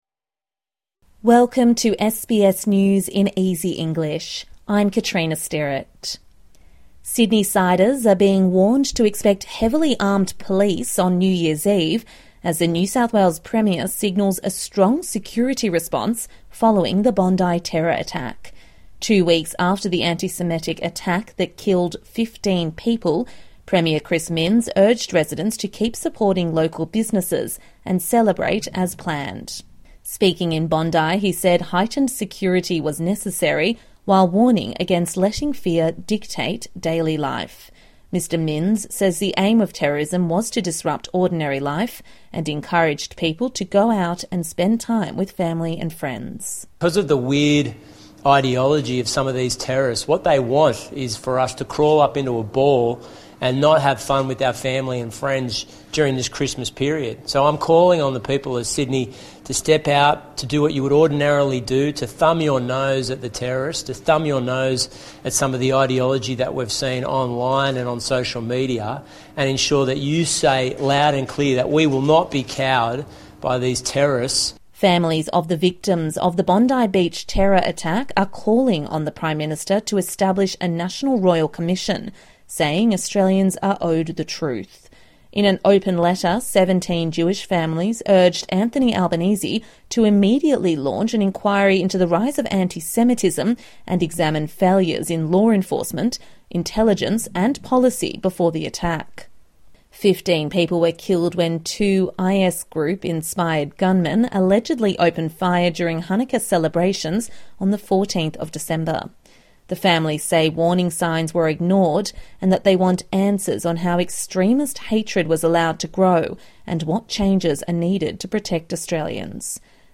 A daily 5 minute bulletin for English learners and people with a disability.